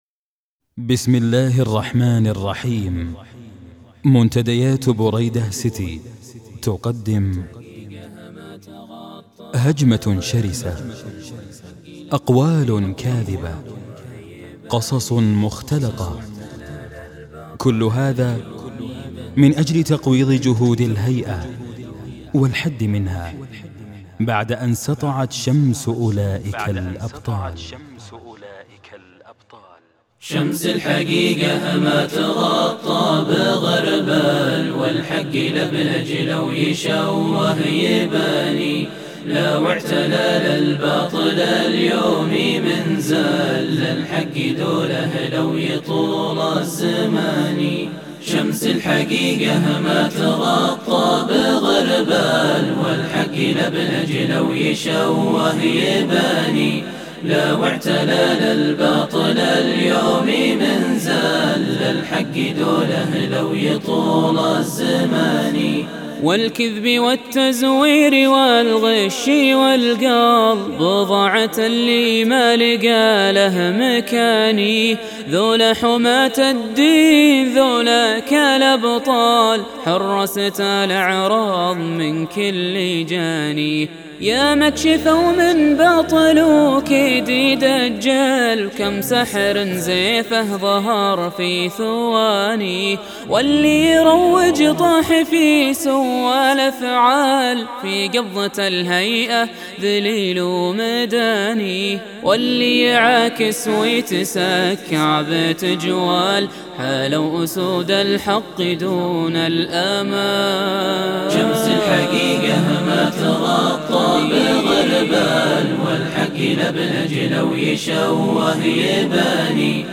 قصيدة متواضعة في الذب عن رجال الحسبة
تم هذا العمل في ستديو لمسات ..